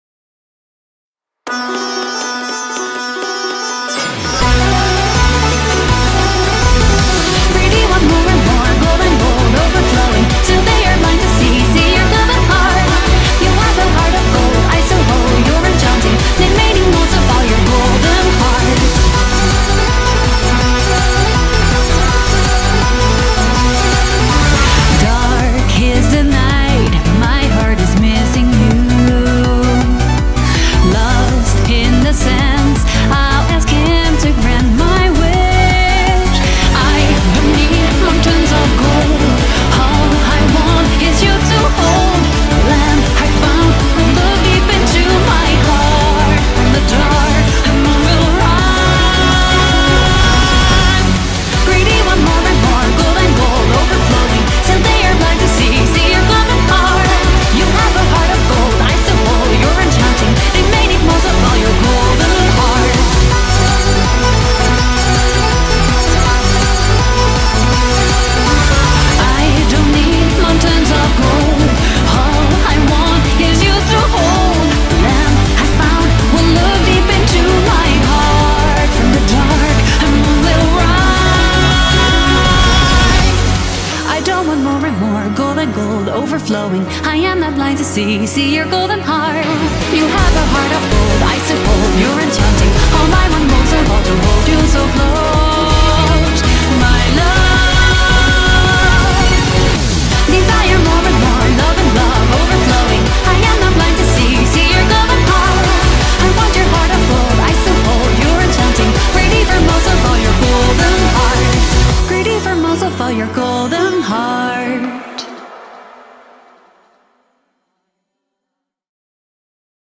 BPM163
Audio QualityPerfect (Low Quality)